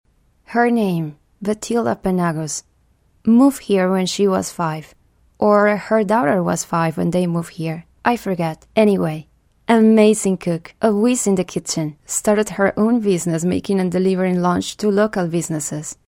English voice over. Spanish accent